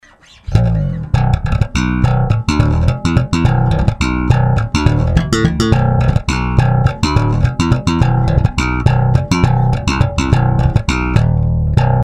slap na pate strune
lowB